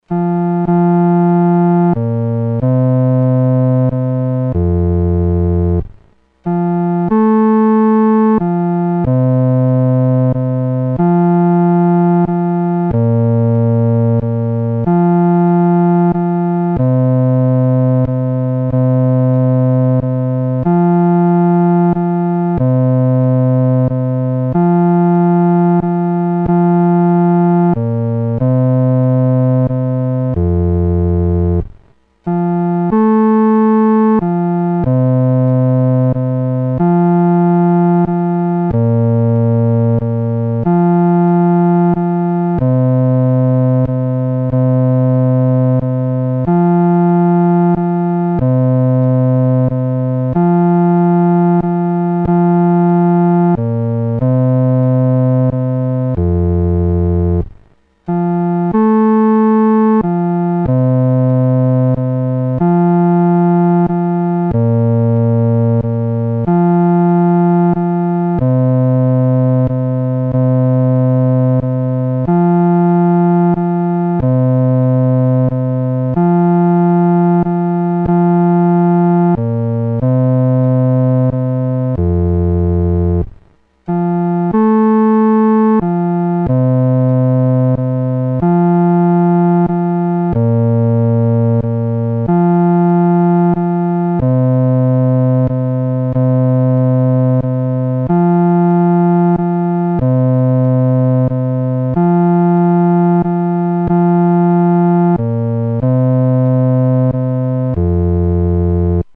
独奏（第四声）